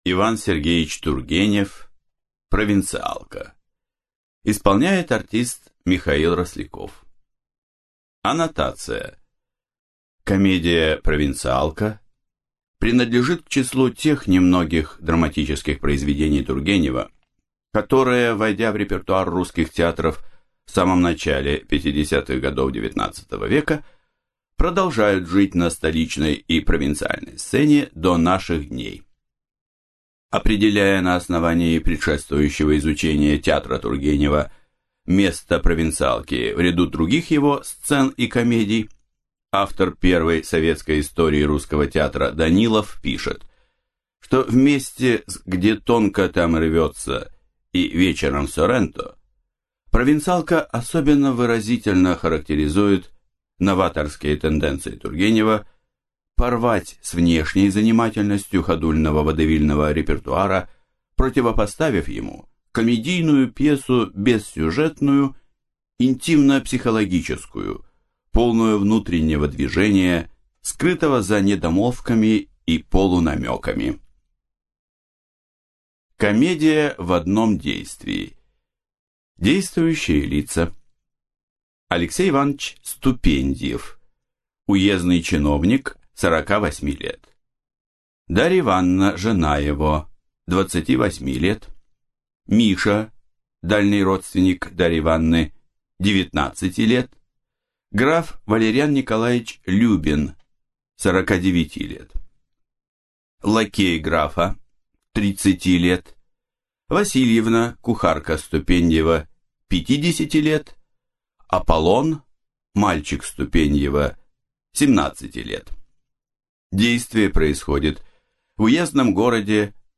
Аудиокнига Провинциалка | Библиотека аудиокниг